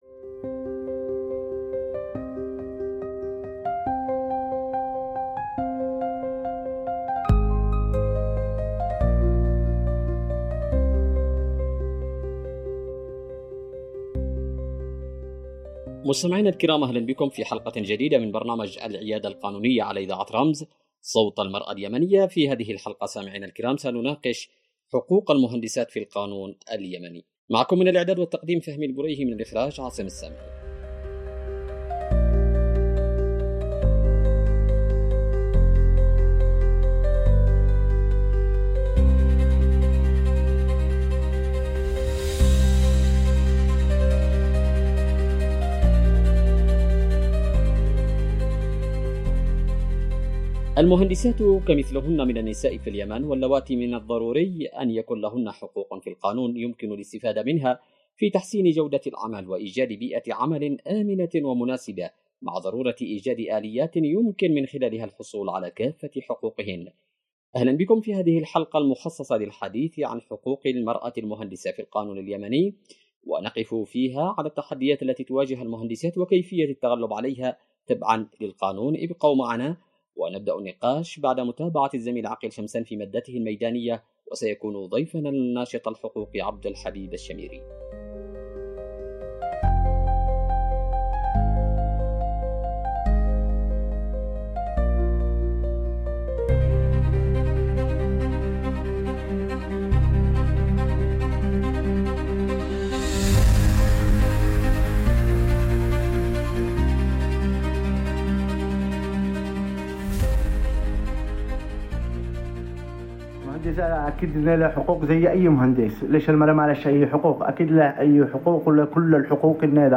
عبر إذاعة رمز